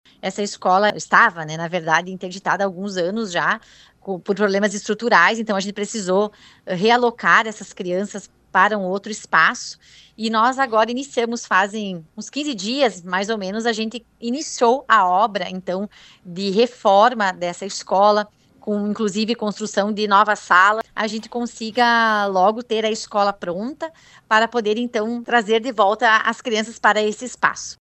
A escola municipal infantil pró-infância de Pejuçara passa por reforma e ampliação. Durante entrevista ontem à tarde no programa de Pejuçara, pela RPI, a secretária de Educação, Emanueli Basso Quaini, disse que até metade desse ano a obra deve estar concluída. (Abaixo, sonora da secretaria Emanueli).